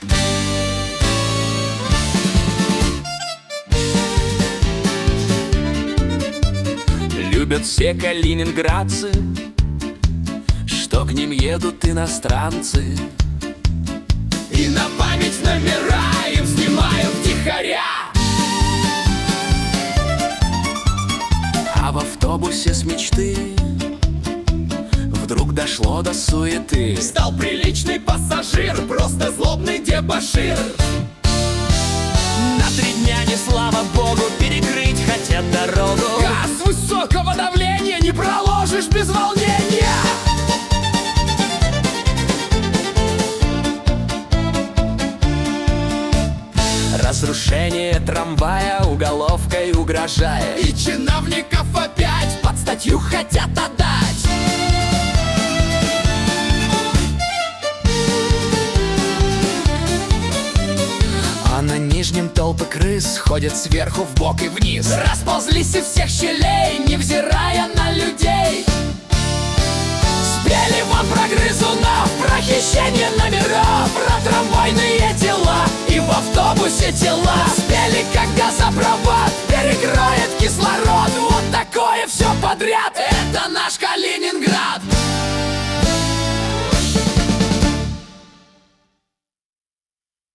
Куплеты на злобу дня о главных и важных событиях